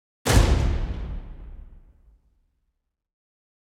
Suspense 1 - Stinger 1.wav